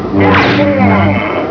Dark Vader complaining